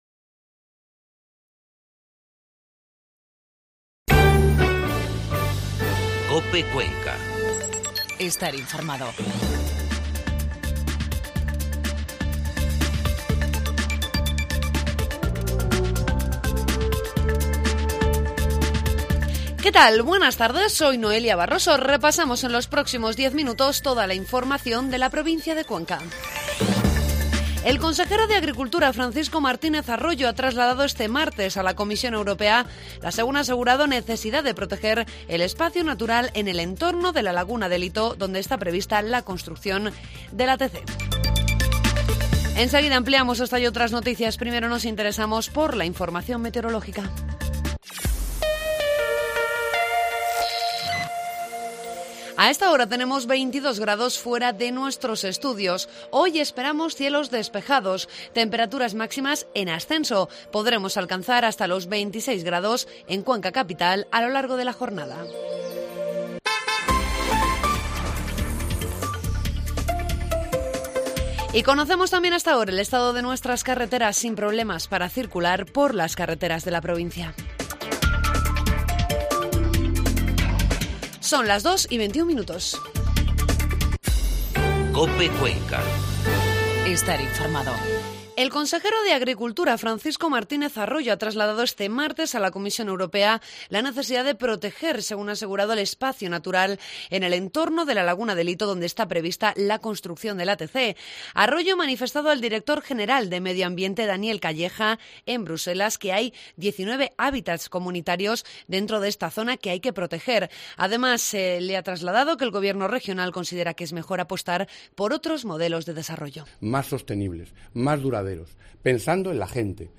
AUDIO: Informativo mediodía